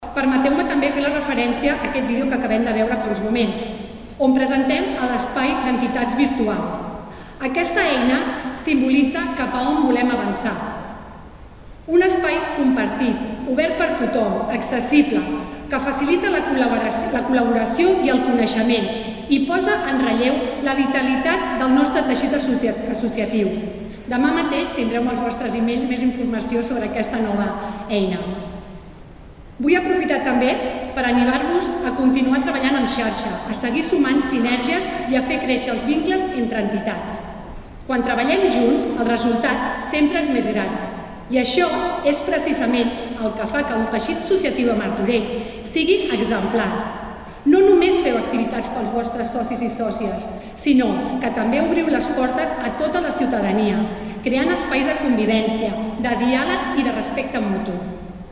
La 29a Trobada d’Entitats ha reconegut l’associacionisme a Martorell aquest vespre a El Progrés, en una gala amb prop de 400 assistents.
Maria Àngels Soria, regidora de Teixit Associatiu